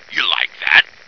flak_m/sounds/male2/int/M2yalikethat.ogg at d2951cfe0d58603f9d9882e37cb0743b81605df2